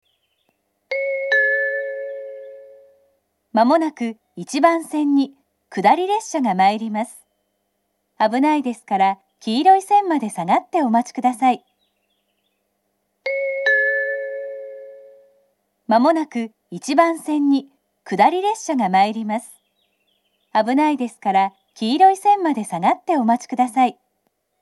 この駅はかつて鹿島台等で使用されていた古いタイプの放送を使用していましたが、放送装置更新により盛岡支社管内で多く使われているタイプへ変更されました。
１番線接近放送 下り本線です。